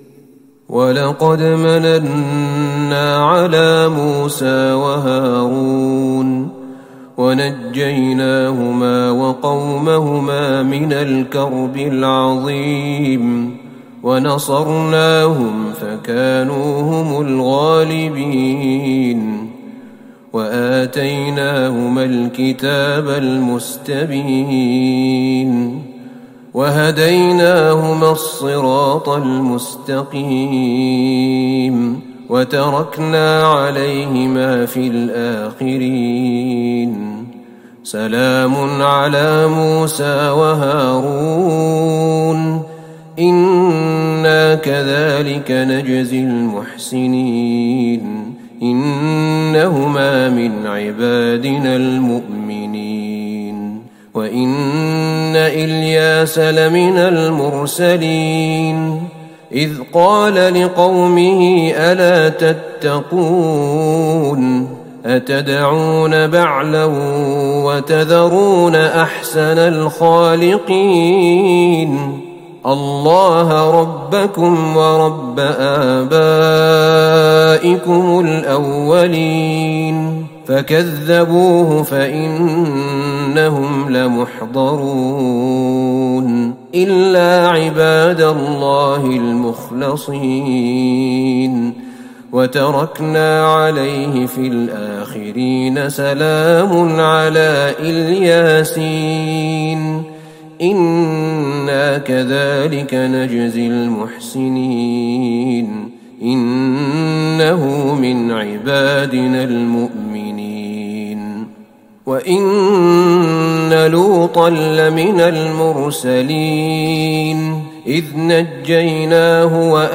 تهجد ٢٥ رمضان ١٤٤١هـ من سورة الصافات { ١١٤-النهاية } وص { ١-٤٤ } > تراويح الحرم النبوي عام 1441 🕌 > التراويح - تلاوات الحرمين